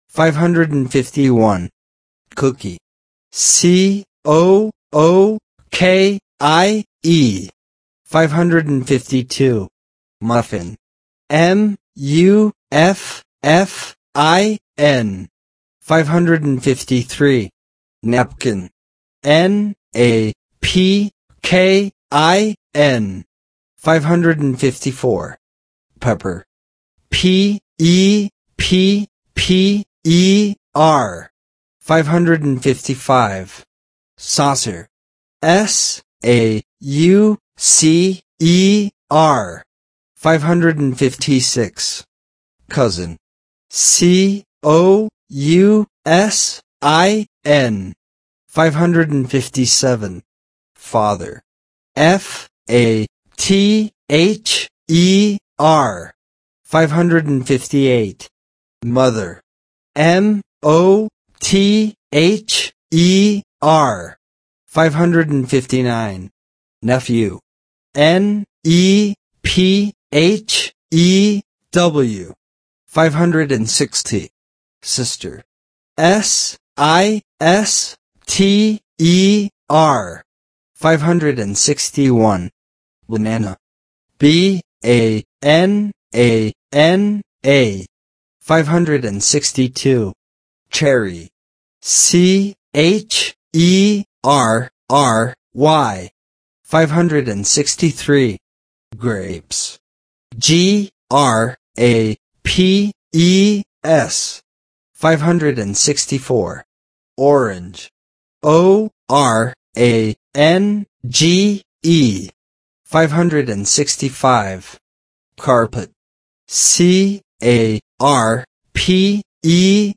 Spelling Exercises